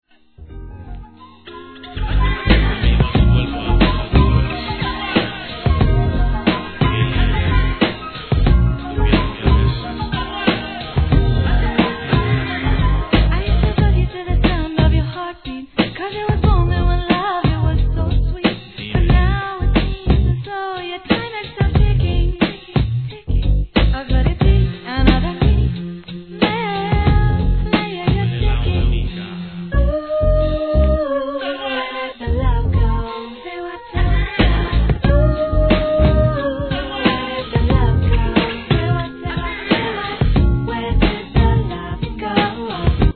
1. HIP HOP/R&B
使いに透き通るようなSWEET VOICE!!